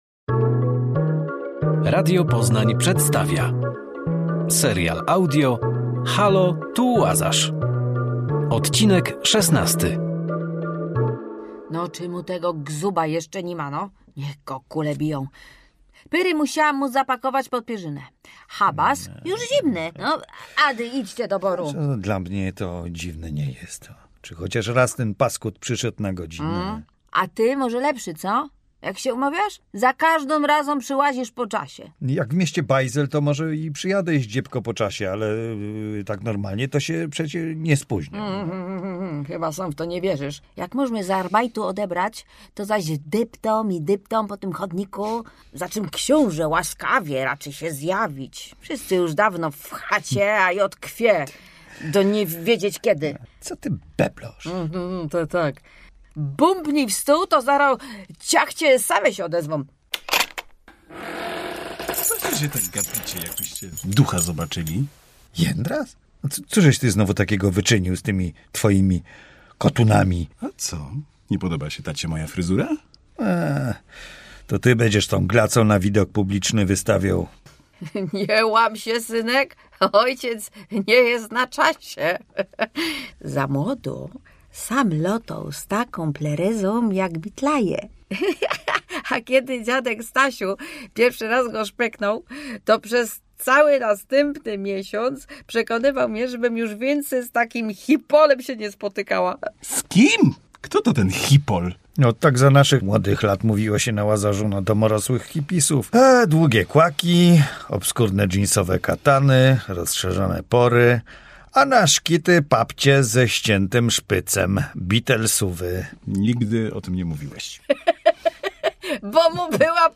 Serial audio Radia Poznań